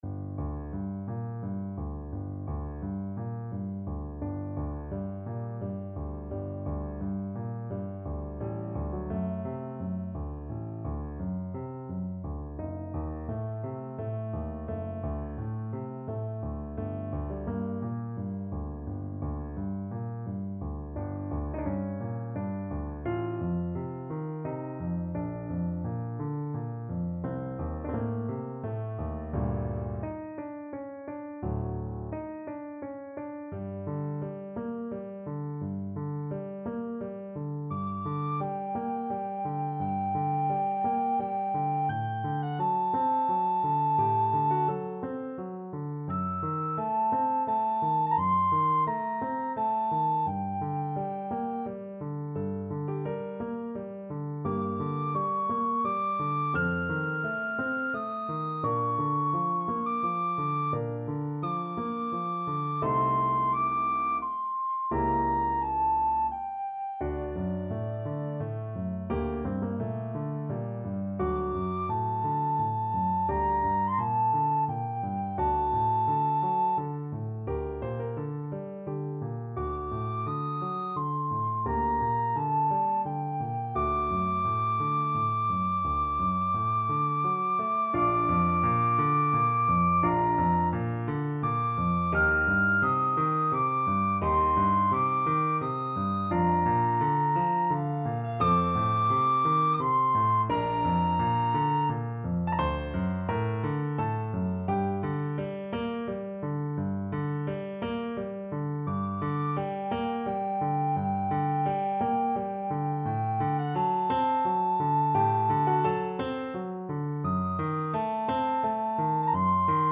Classical Donizetti, Gaetano Una Furtiva Lagrima Romanza from L'Elisir d'amore Soprano (Descant) Recorder version
6/8 (View more 6/8 Music)
G minor (Sounding Pitch) (View more G minor Music for Recorder )
Larghetto = c. 86
Classical (View more Classical Recorder Music)